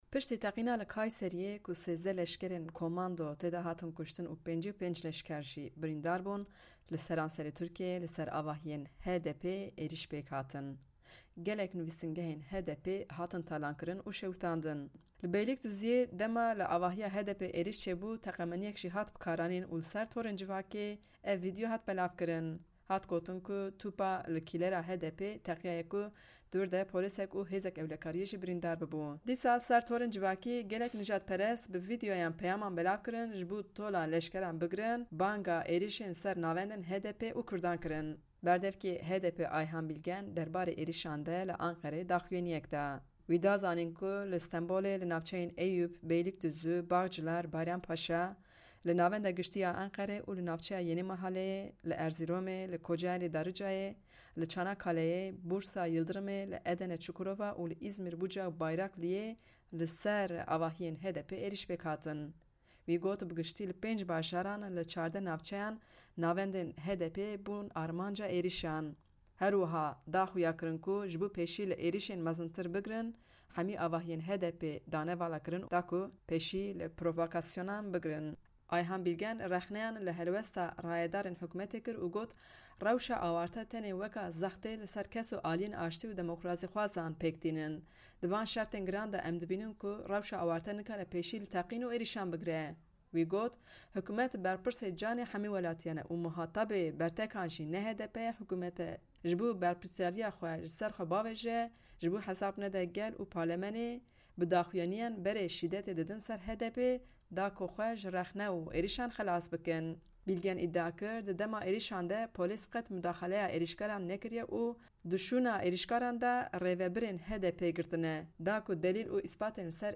Berdevkê HDPê Ayhan Bîlgen derbarê êrîşan de daxuyaniyek da.